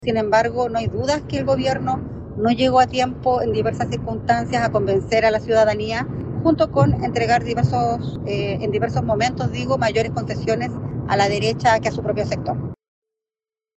En tanto, la diputada Nathalie Castillo dijo que “las responsabilidades son compartidas por todos quienes conformamos el oficialismo (…) pero debemos tener una honesta autocrítica para conformar un bloque de oposición robusto”.